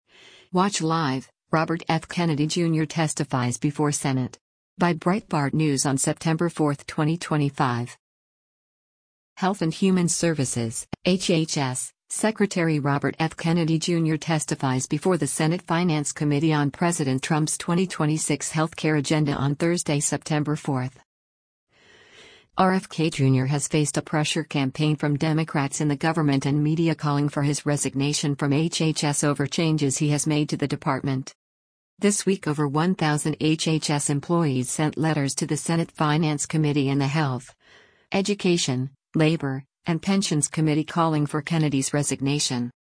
Health and Human Services (HHS) Secretary Robert F. Kennedy Jr. testifies before the Senate Finance Committee on President Trump’s 2026 health care agenda on Thursday, September 4.